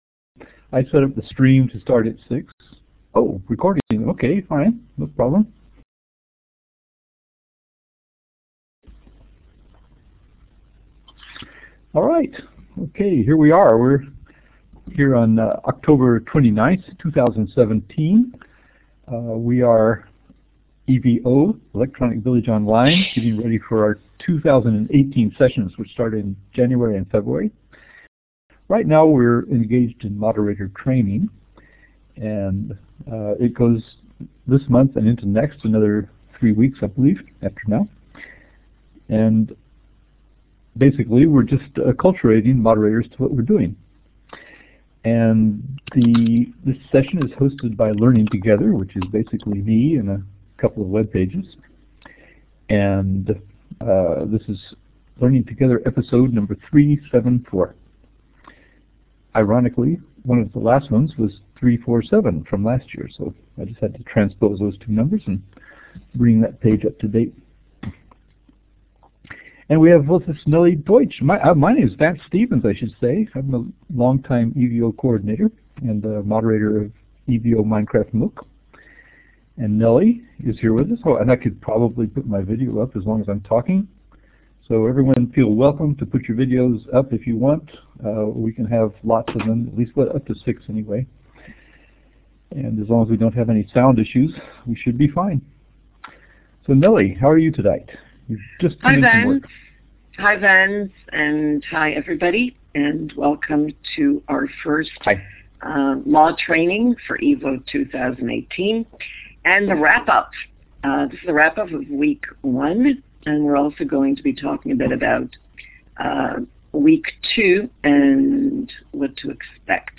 Where? Blackboard Collaborate
On Sunday, Oct 29, at 1400 UTC, Learning2gether hosted the 1st live event in this year’s EVO2018 Moderator Training series. This is where the moderators whose proposals were accepted receive any help needed over 4 weeks time to bring them up to prime time readiness.